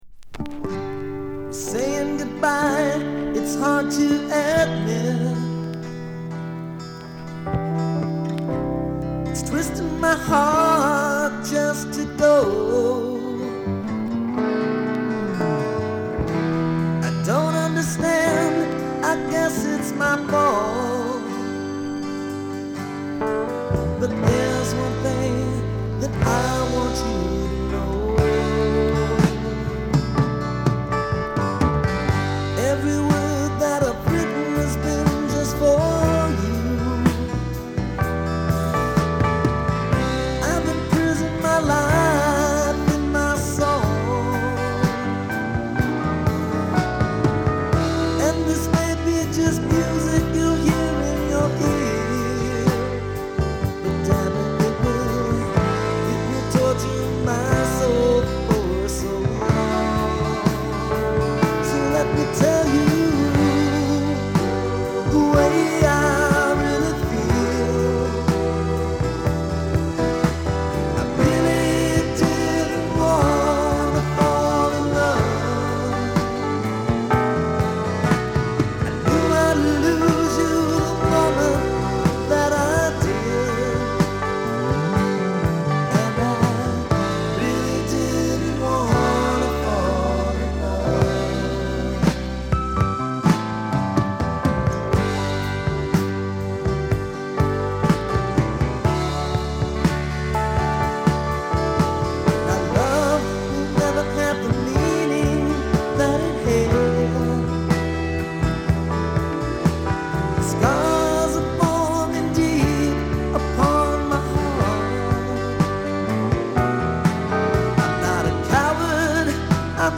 広がりを感じさせるハード・ポップロック。時折繊細さを見せるところがヨシ。